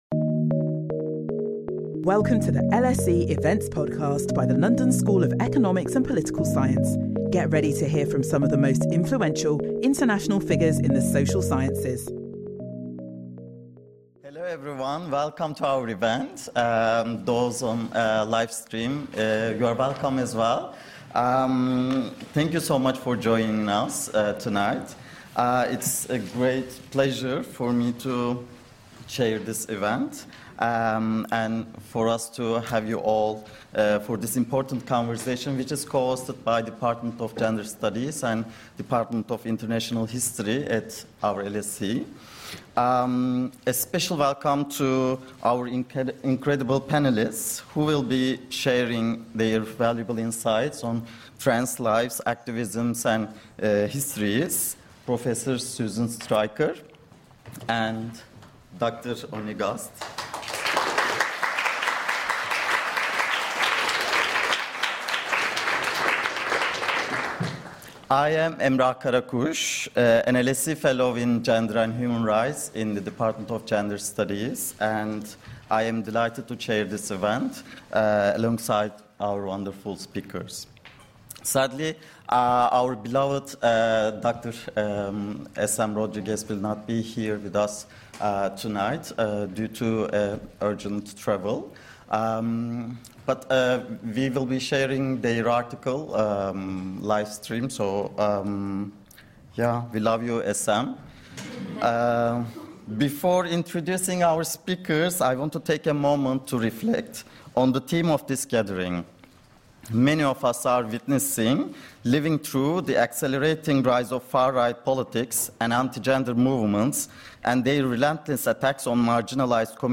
This thought-provoking conversation will bring together diverse expertise to critically examine and address the urgent socio-political challenges of our time.